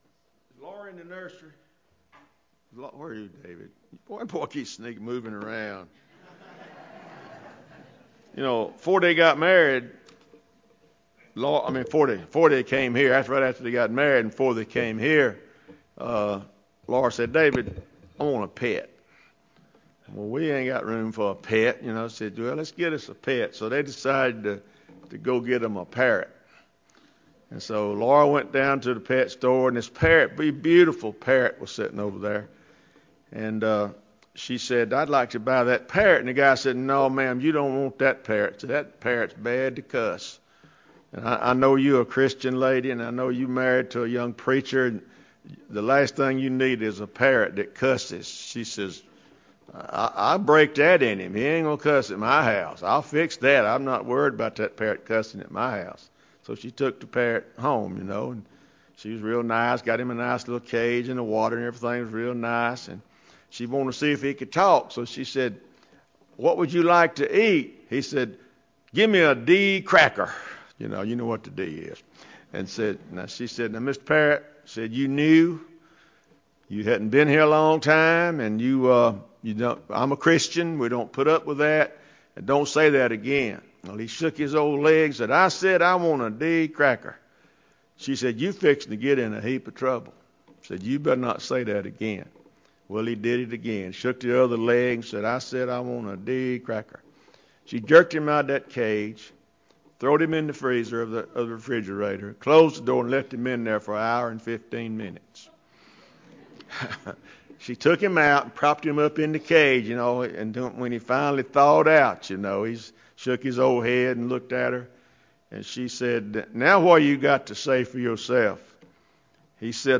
Fall 2015 Revival